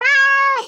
meow.CtvcfQR4.mp3